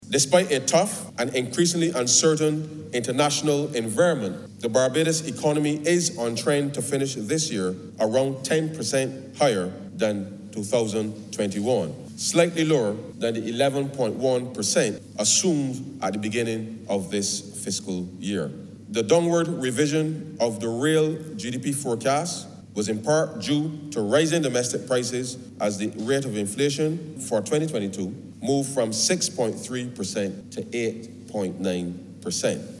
This has been disclosed by minister in the ministry of finance Ryan Straughn delivering a fiscal and economic update in the House of Assembly.
Minister in the ministry of finance Ryan Straughn.